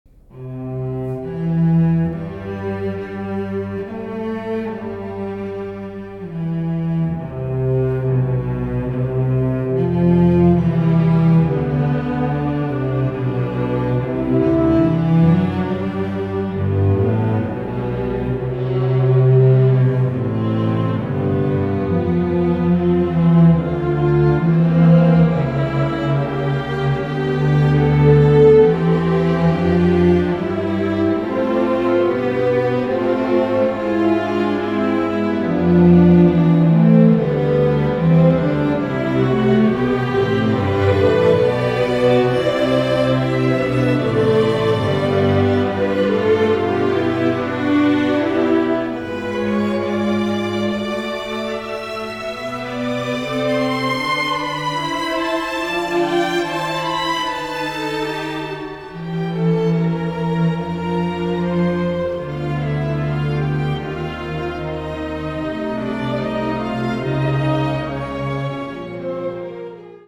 Chapel
violins:
violoncelli:
contrabass: